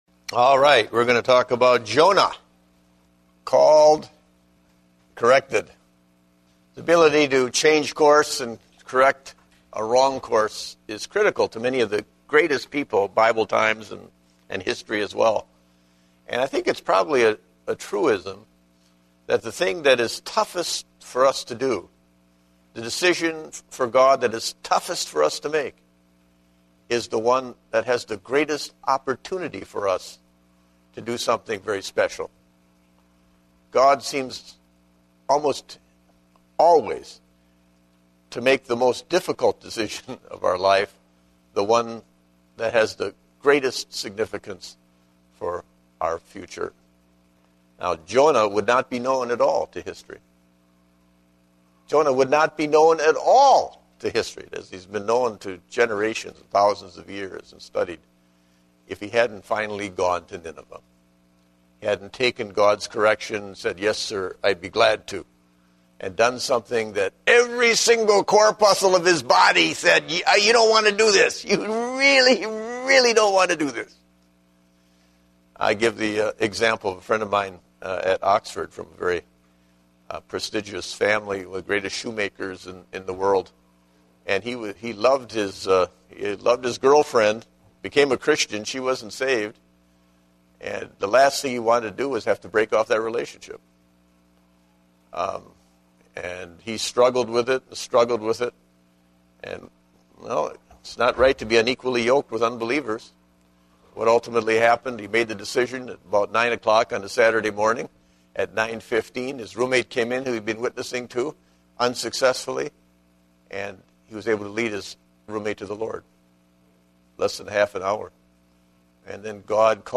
Date: October 3, 2010 (Adult Sunday School)